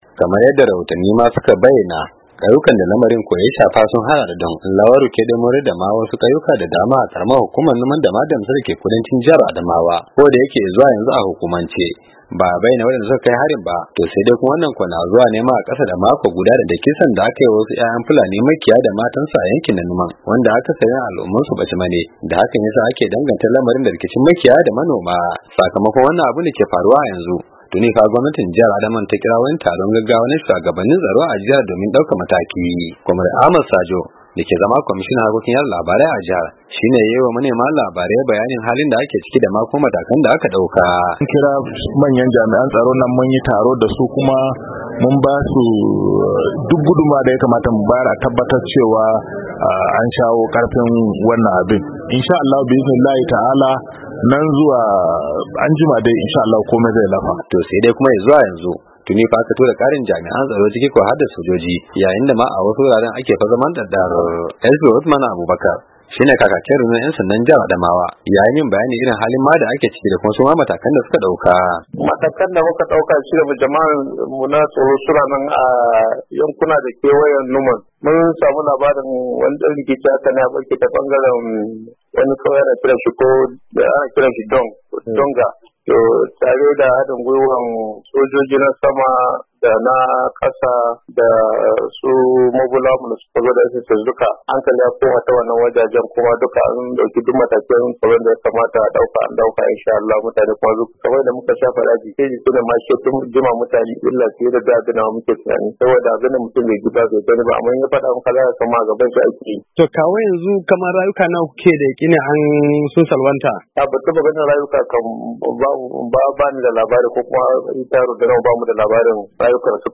Kwamared Ahmad Sajo, kwamishinan harkokin yada labarai a jihar ya yiwa manema labarai bayanin halin da ake ciki da kuma matakan da aka dauka.